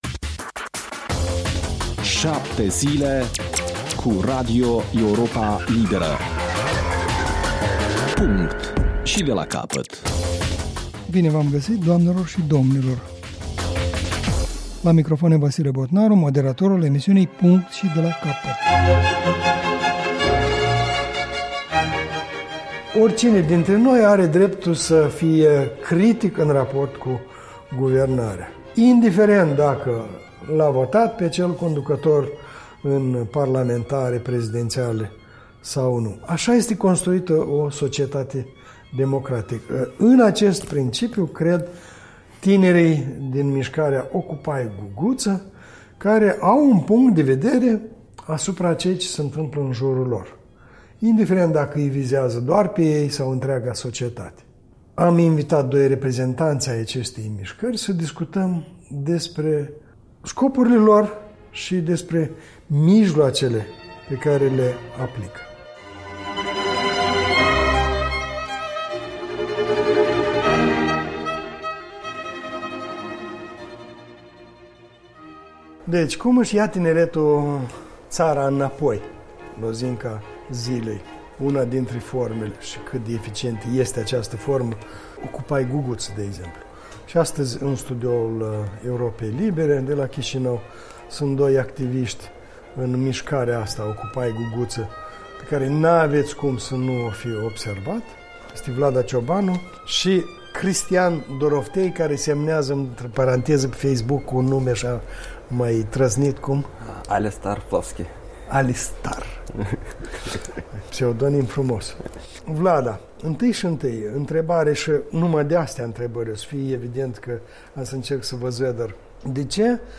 discută cu activiștii mișcării Occupy Guguță